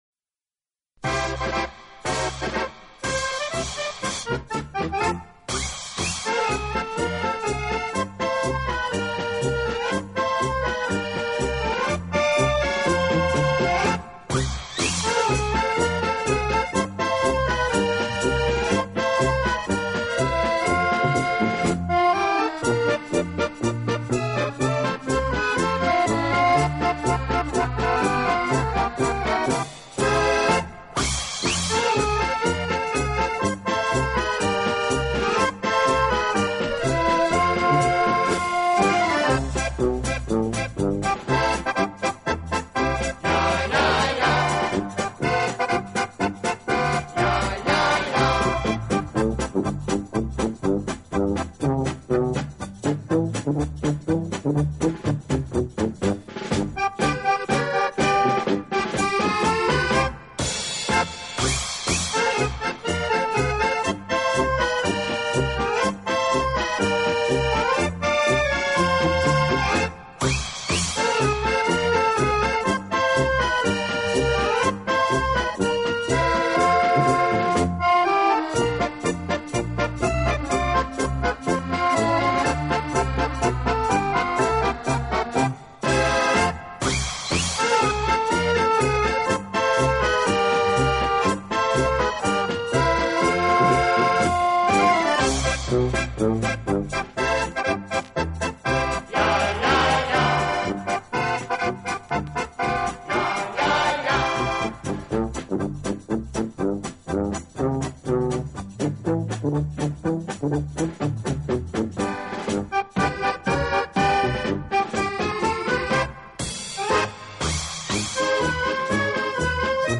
【手风琴】